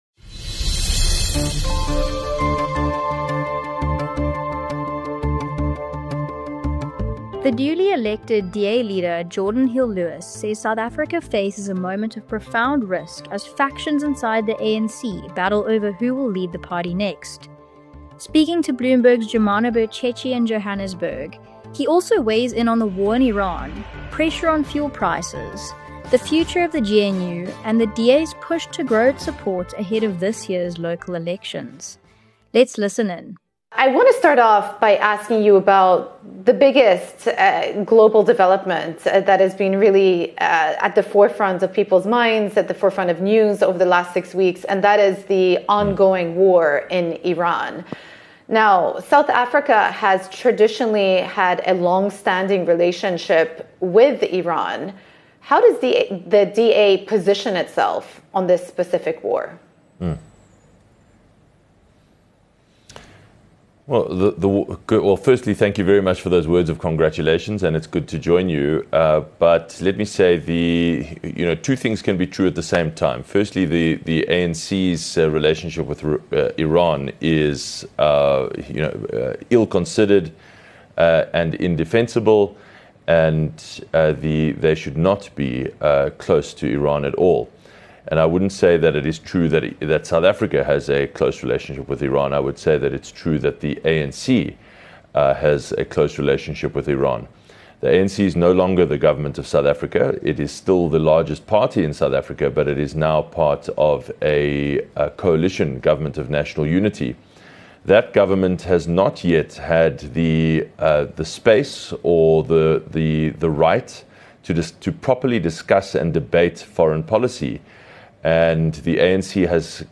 Newly-elected DA leader Geordin Hill-Lewis has warned that the ANC’s looming leadership battle could destabilise both South Africa and the Government of National Unity. In an interview with Bloomberg’s Joumanna Bercetche, he argues that bitter factional infighting inside the ruling party poses a major threat to reform, while also outlining the DA’s stance on Iran, fuel-price pressure and its plan to grow support ahead of the local elections.